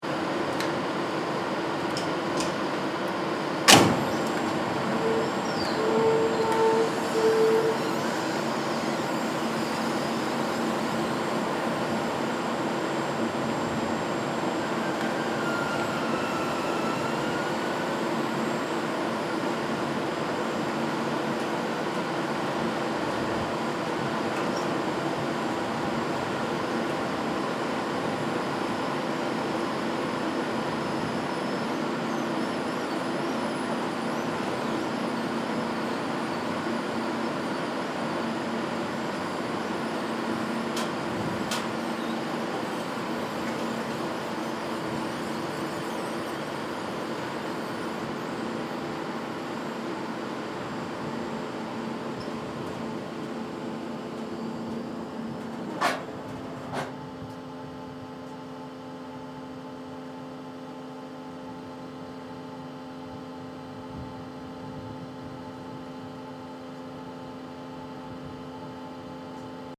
Soundscape: La Silla NTT dome closing
ss-ls-ntt-dome-cl_mono.mp3